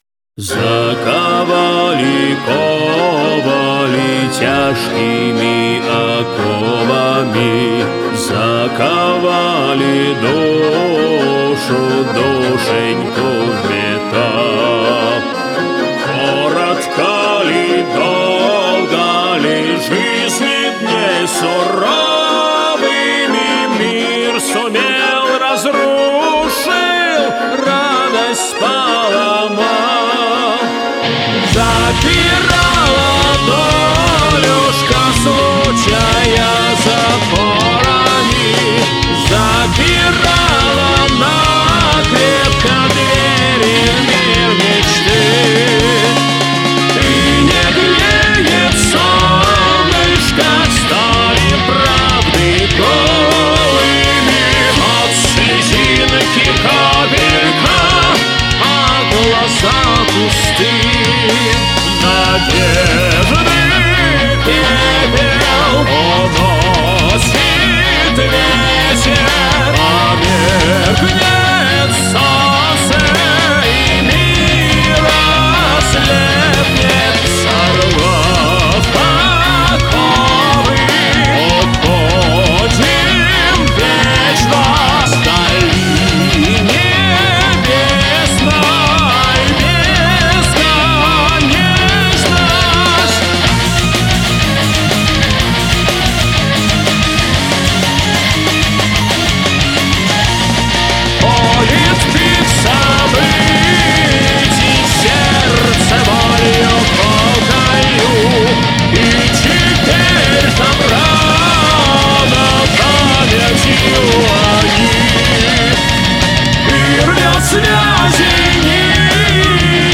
Металл Песни Ковали